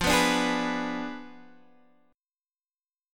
Fdim7 chord